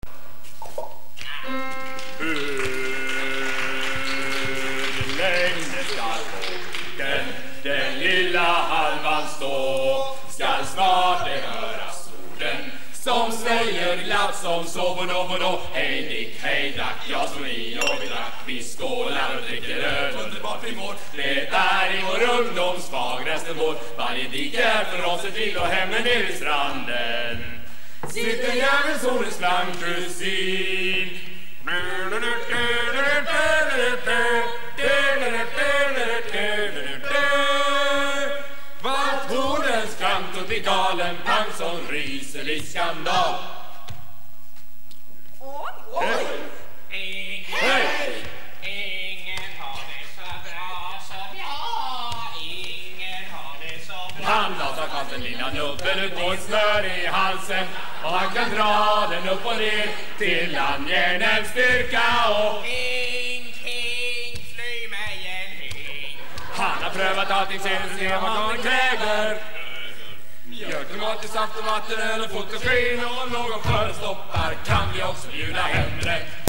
Låtar som av någon anledning valts ut från spex-86.
Fyllevisa i vilken ett antal mer eller mindre kända spritvisor blandas på ett mycket förtjänstfullt sätt.